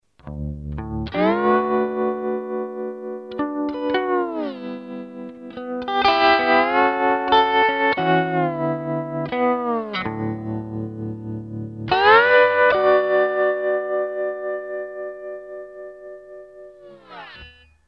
ich wußte, ich hatte noch mehr Soundfiles mit der Lap in D6-Tuning.